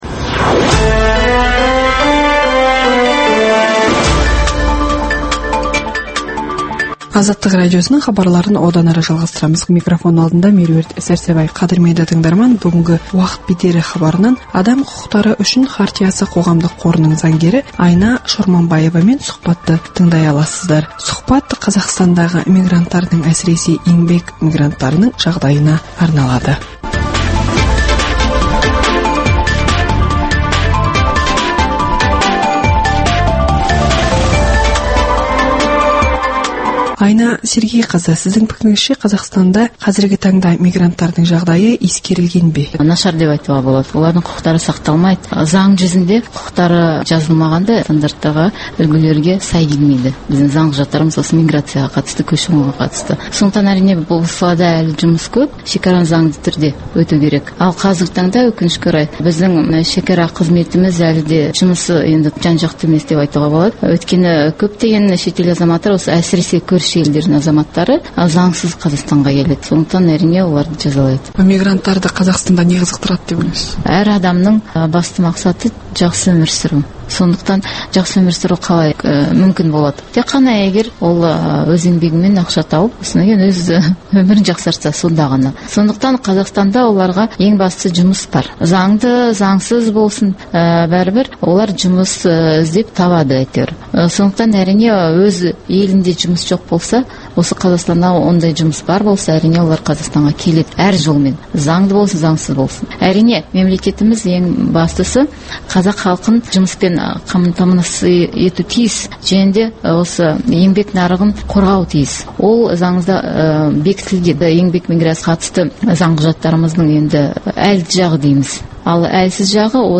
сұқбат Қазақстандағы еңбек мигранттарының құқықтары қалай қорғалып отыр және олардың жағдайлары қандай деген мәселелер тұрғысында өрбіді.